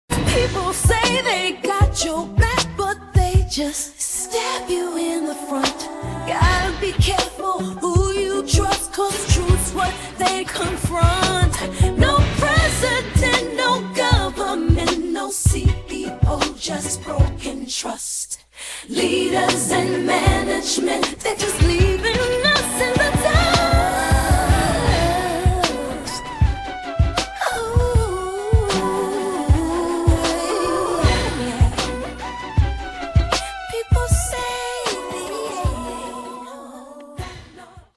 R & B